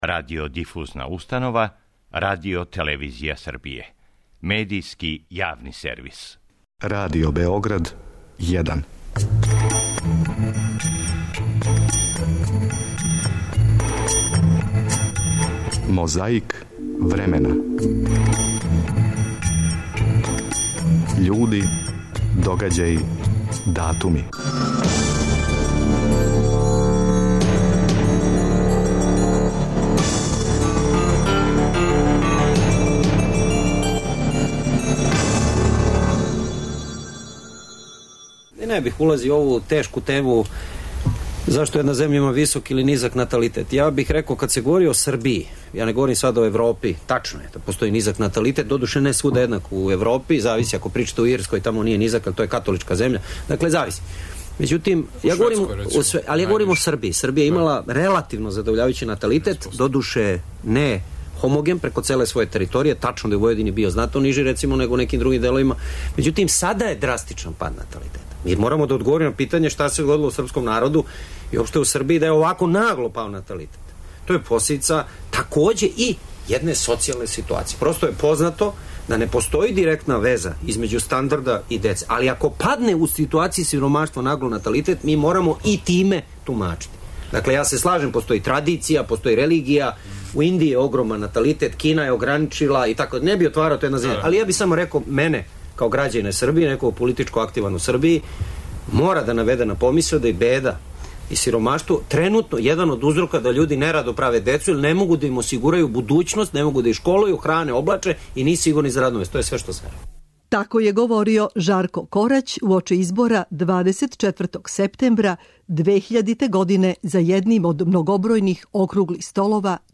Микрофони раде, магнетофони снимају.
Боравак Јосипа Броза друга Тита на Златибору сликан микрофоном 23. септембра 1959.